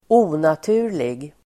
Uttal: [²'o:natu:r_lig]